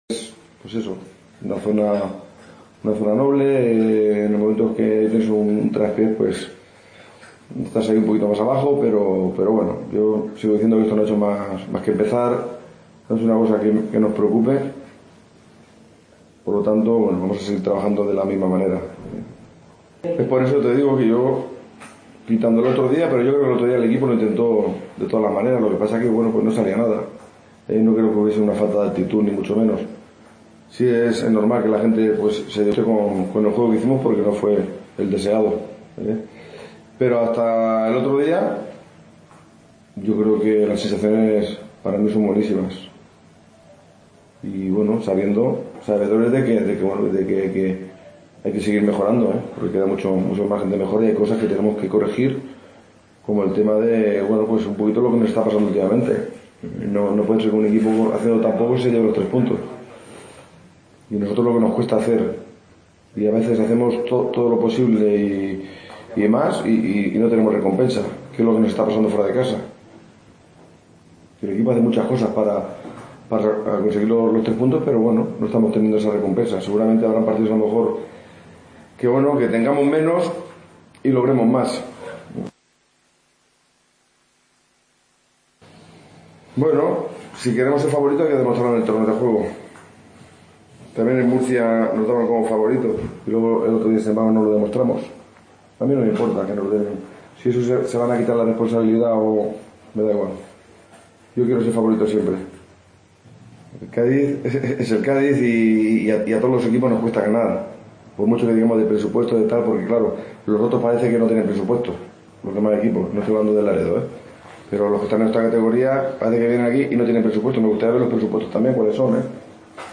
AUDIO: Segunda parte de la tertulia en De Otero en la previa de la Copa del Rey